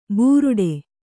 ♪ būruḍe